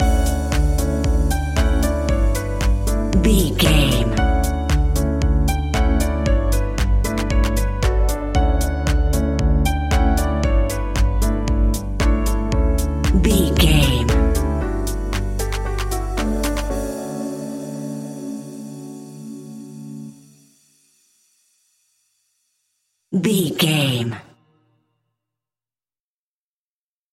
Ionian/Major
D
uplifting
energetic
bouncy
electric piano
drum machine
synthesiser
electro house
synth leads
synth bass